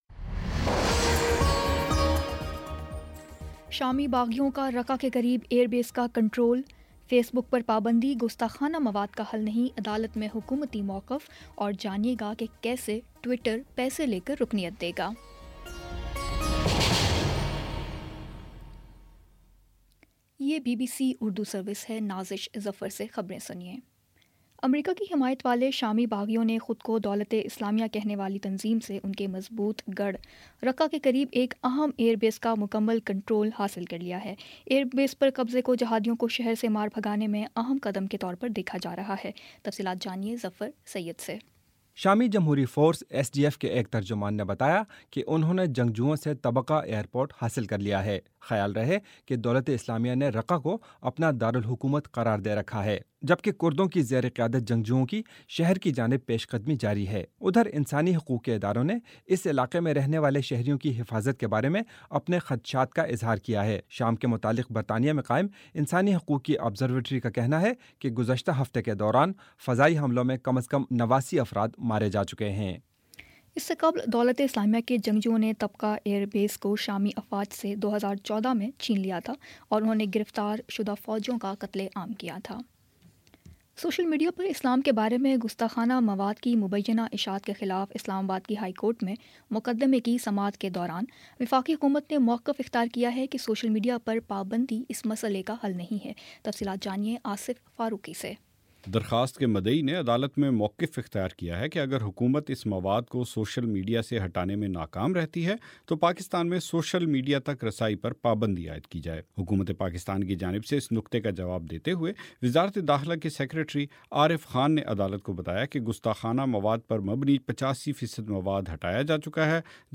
مارچ 27 : شام پانچ بجے کا نیوز بُلیٹن